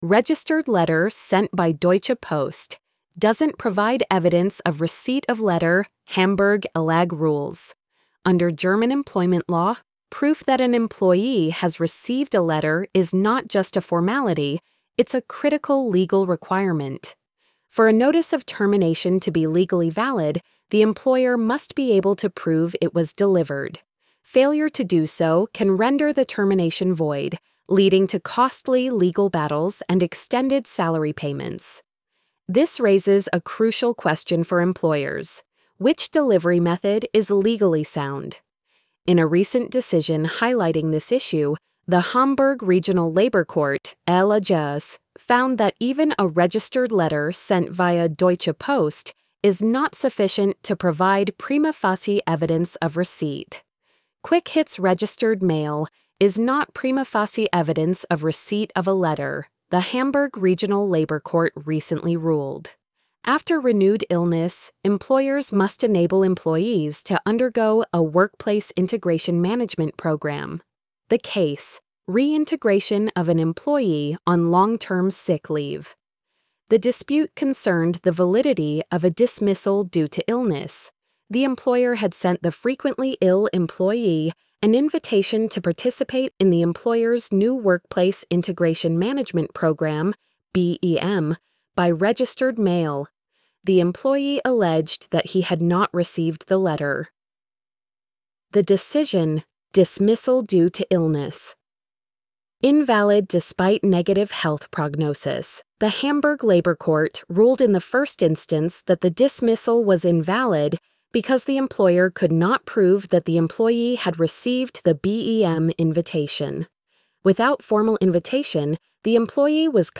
registered-letter-sent-by-deutsche-post-doesnt-provide-evidence-of-receipt-of-letter-hamburg-lag-rules-tts.mp3